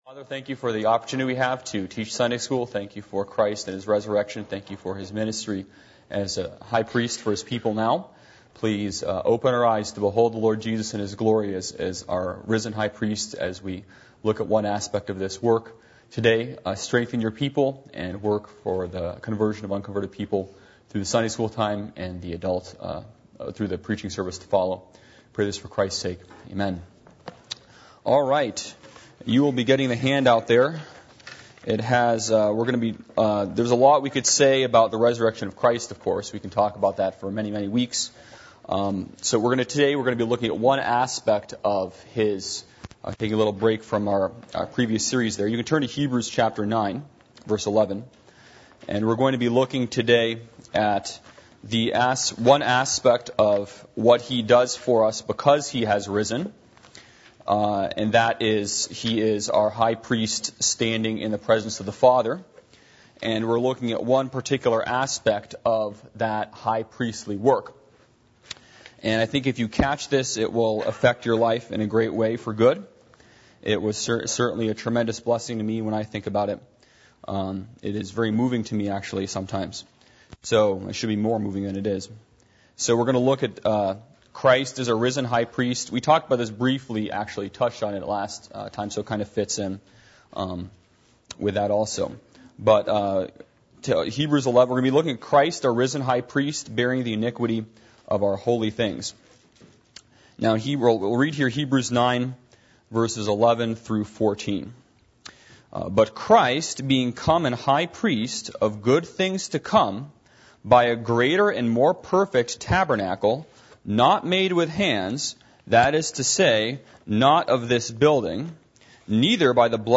Passage: Hebrews 9:11-14, Hebrews 9:24-25 Service Type: Adult Sunday School